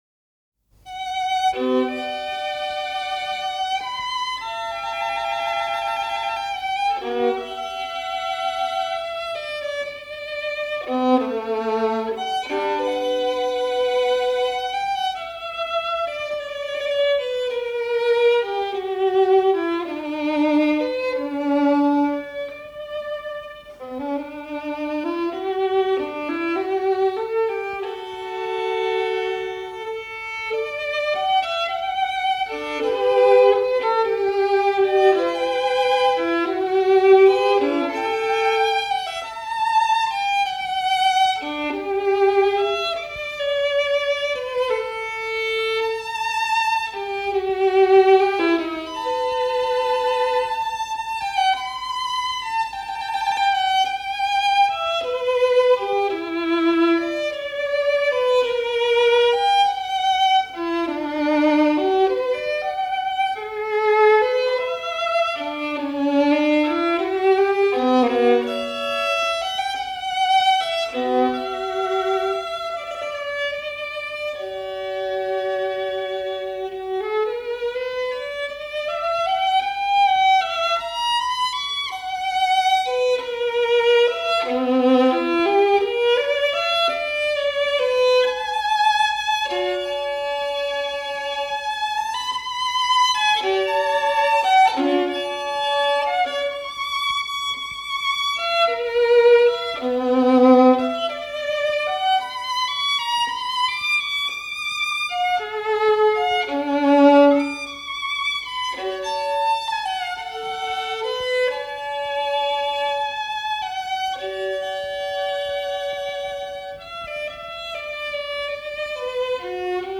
SIDE 2 -- Sonata No.2 in B minor (Partita No. 1)